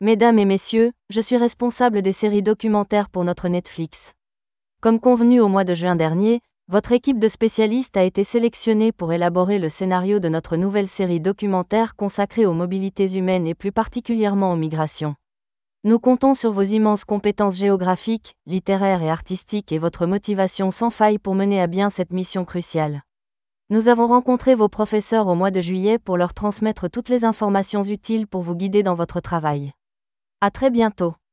Message téléphonique adressé aux élèves :
voicebooking-speech-copie.wav